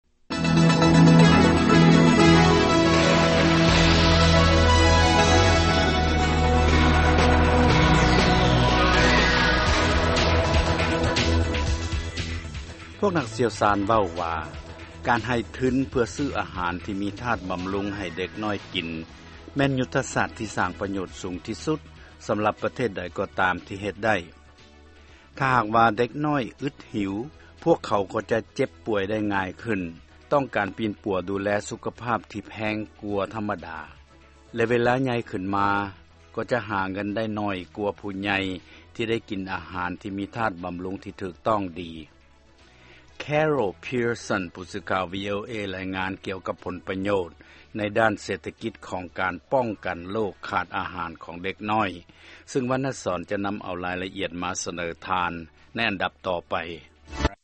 ຟັງລາຍງານເດັກເປັນໂຣກຂາດອາຫານ